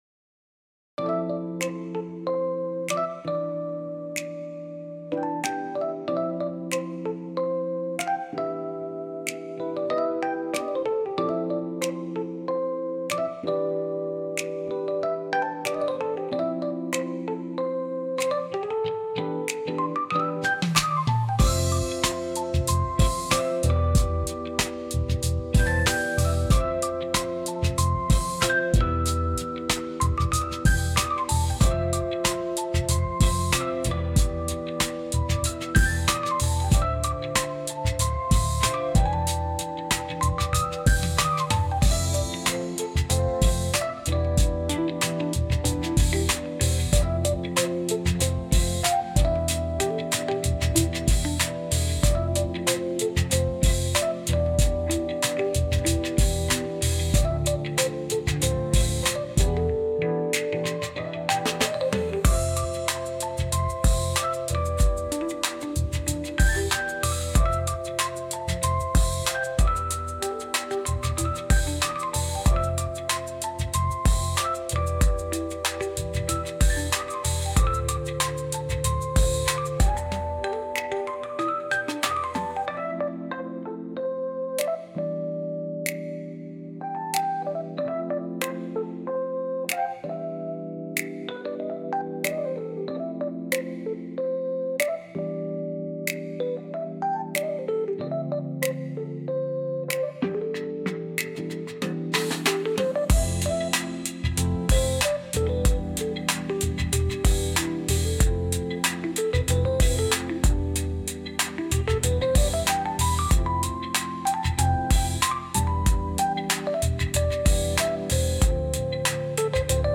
BGMのダウンロード